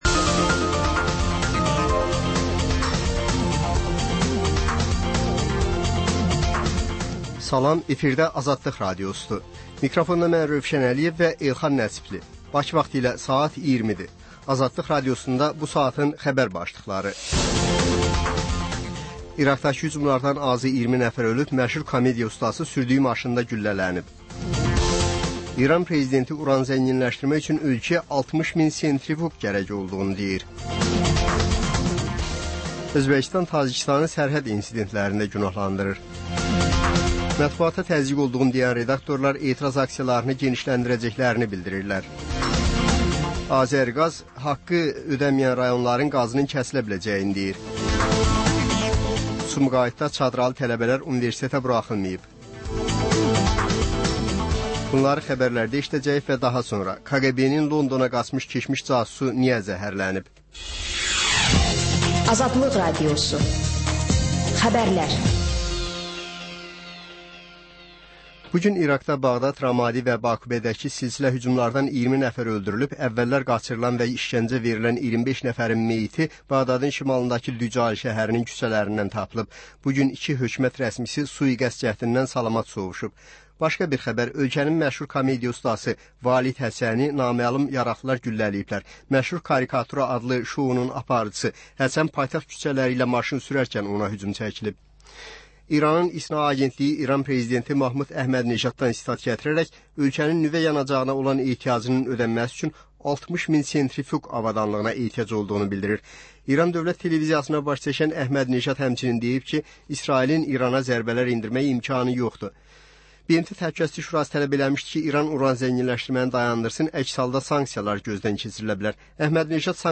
Xəbərlər, reportajlar, müsahibələr. Hadisələrin müzakirəsi, təhlillər, xüsusi reportajlar.